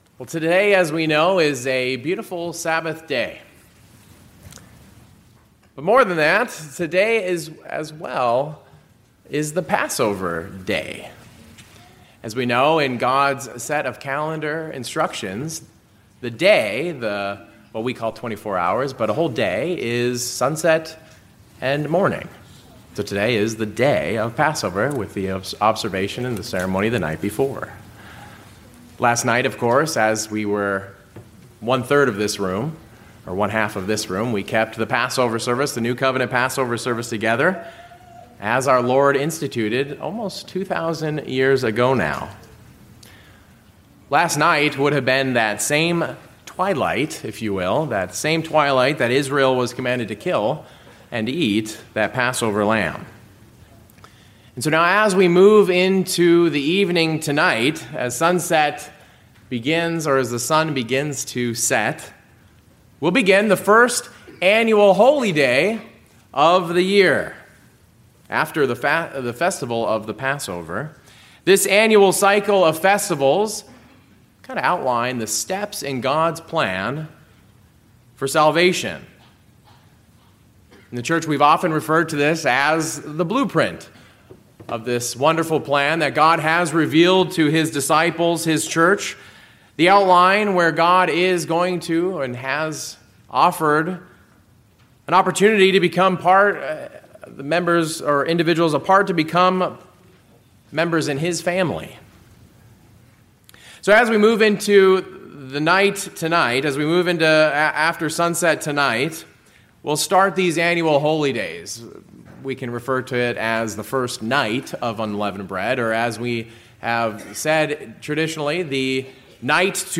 A review of the Night to Be Much Observed, how it is the night that begins the Days of Unleavened Bread. The sermon considers and examines connections and spiritual meanings between Abraham, the Israelites in Egypt, and the New Covenant Church today.